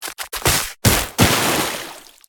Sfx_creature_babypenguin_slip_to_swim_01.ogg